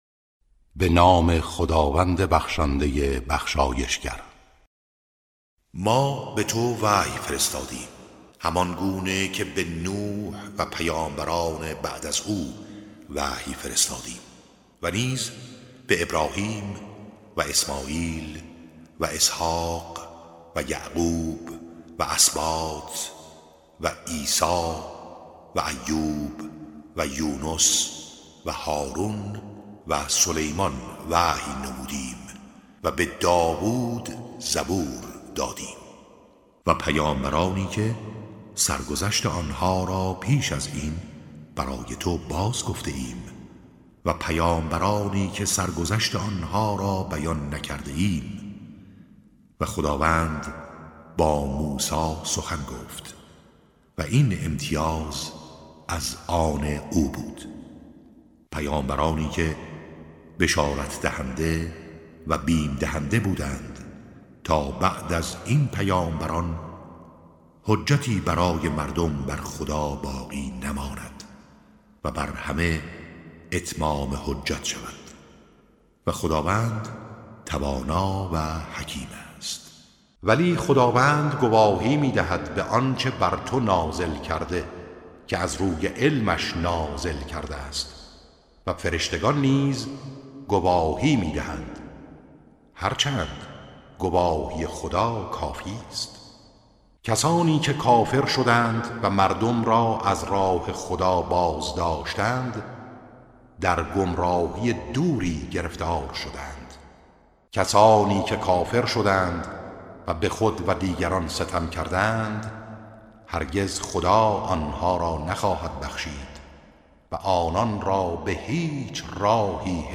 ترتیل سوره(نساء)